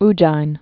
(jīn)